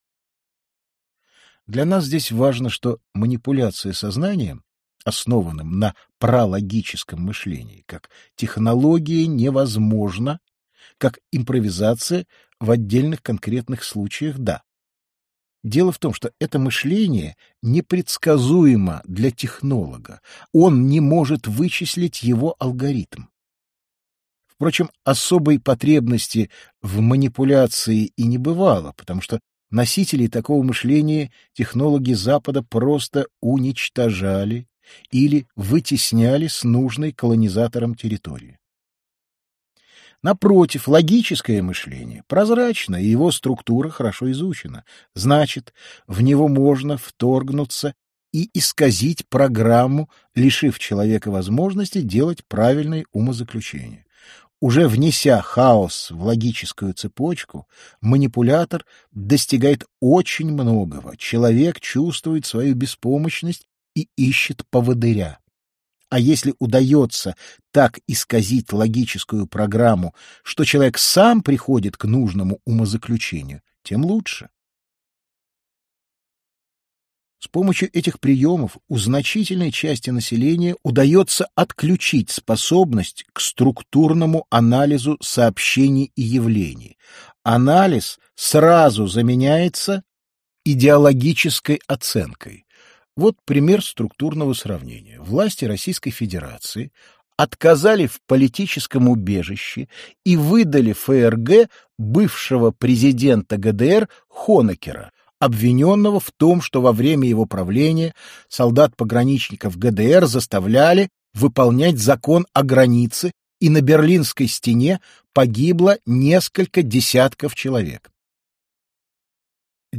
Аудиокнига Манипуляция сознанием. Век XXI. Раздел III. Главные мишени манипуляторов сознанием: духовные процессы | Библиотека аудиокниг